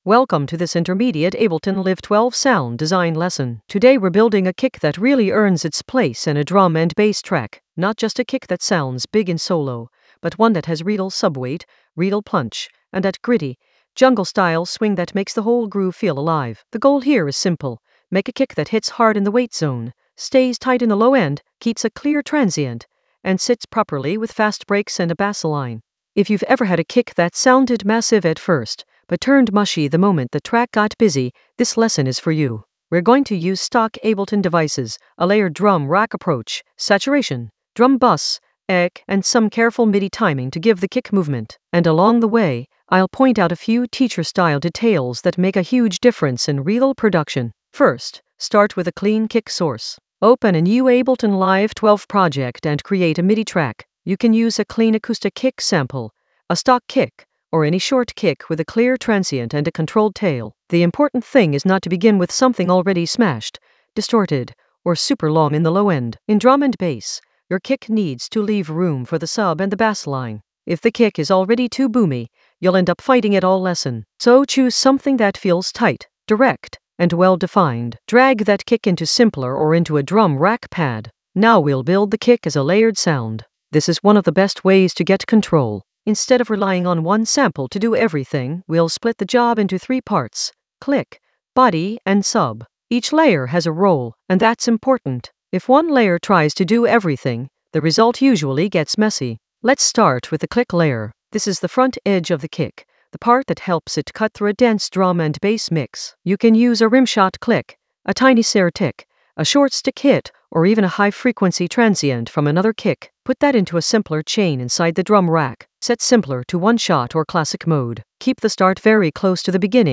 Narrated lesson audio
The voice track includes the tutorial plus extra teacher commentary.
An AI-generated intermediate Ableton lesson focused on Subweight kick weight saturate deep dive with jungle swing in Ableton Live 12 in the Sound Design area of drum and bass production.
subweight-kick-weight-saturate-deep-dive-with-jungle-swing-in-ableton-live-12-intermediate-sound-design.mp3